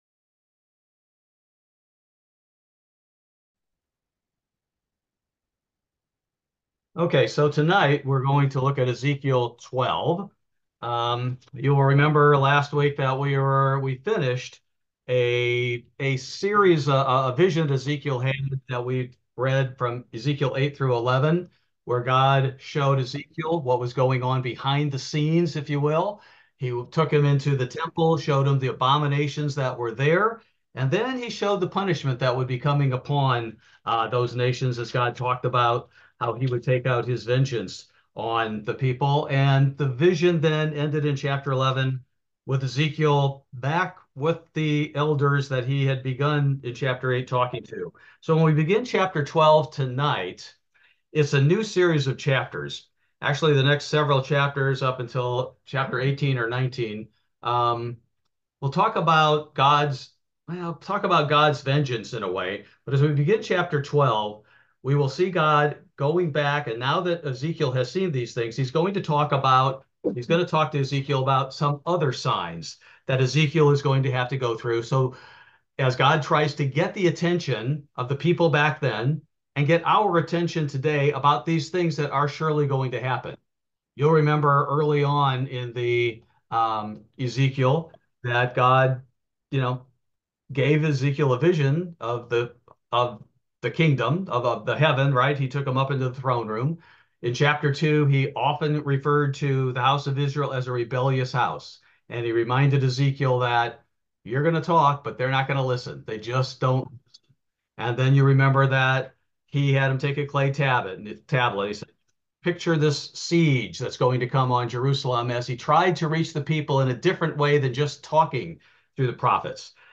Bible Study: June 19, 2024